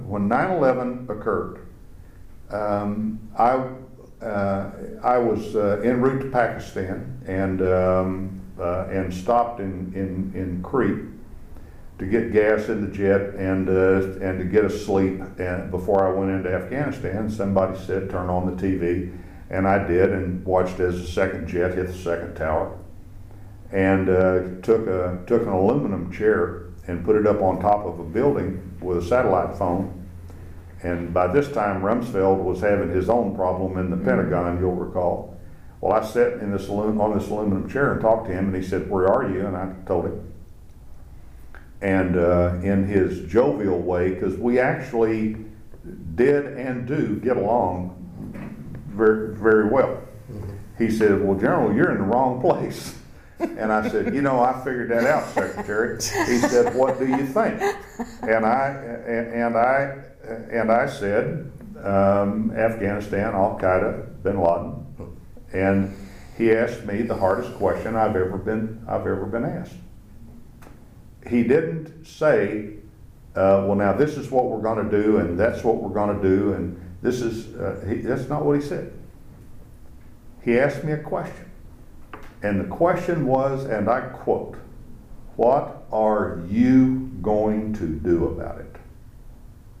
Franks describes his conversations with Secretary of Defense Donald Rumsfeld after the attacks happened. Date: October 22, 2014 Participants Tommy Franks Associated Resources Tommy Franks Oral History The George W. Bush Oral History Project Audio File Transcript